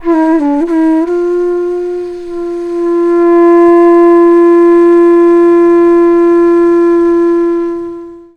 FLUTE-A01 -R.wav